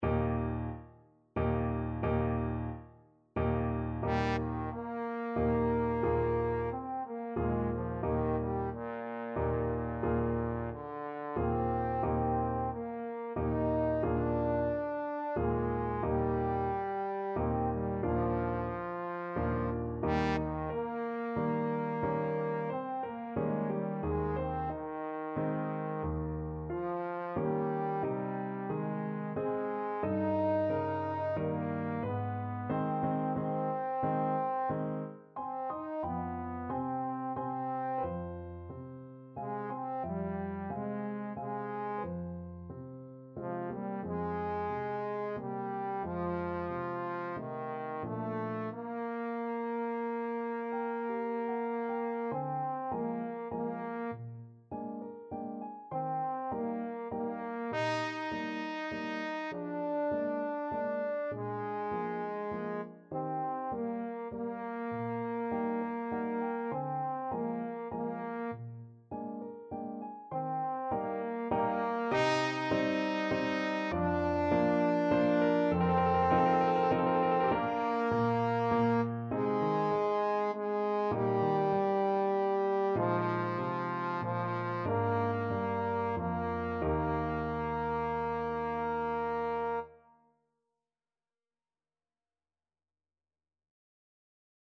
~ = 90 Allegretto moderato
3/4 (View more 3/4 Music)
Classical (View more Classical Trombone Music)